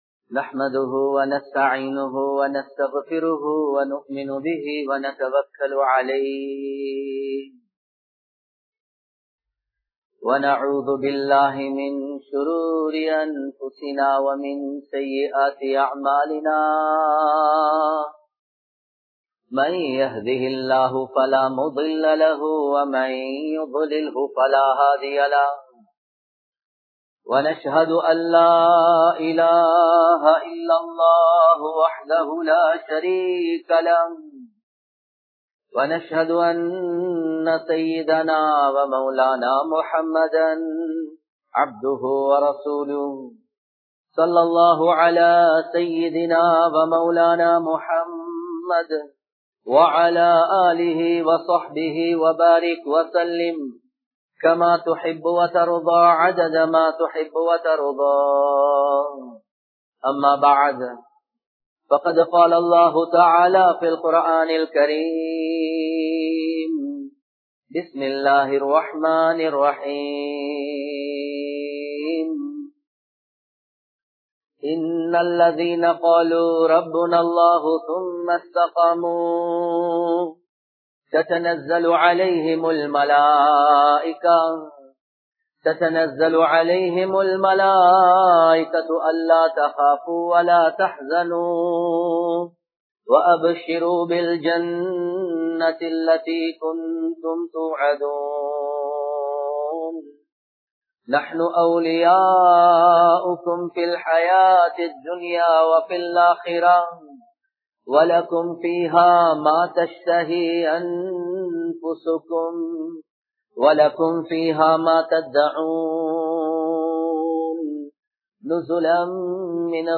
Ottrumaien Avasiyam (ஒற்றுமையின் அவசியம்) | Audio Bayans | All Ceylon Muslim Youth Community | Addalaichenai
Kandy, Kattukela Jumua Masjith